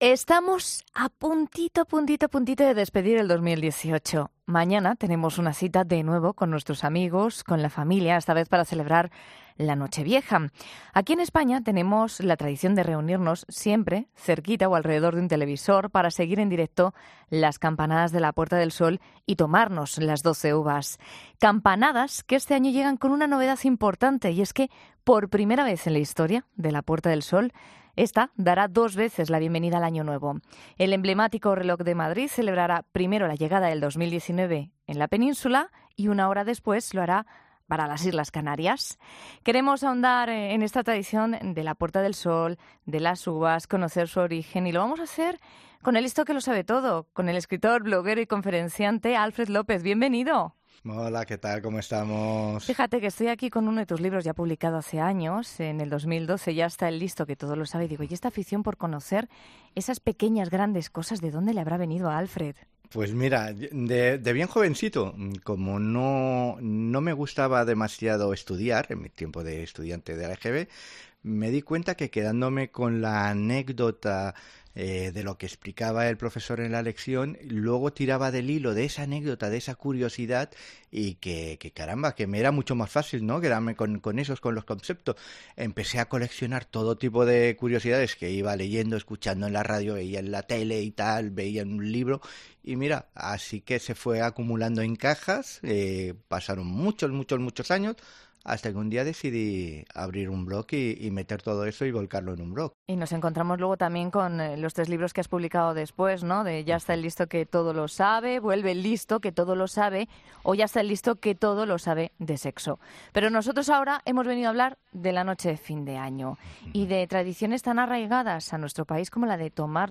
Queremos ahondar en esta tradición de la Puerta del Sol, de las uvas, conocer su origen y lo vamos a hacer con el “listo que lo sabe todo”, con el escritor, bloguero y conferenciante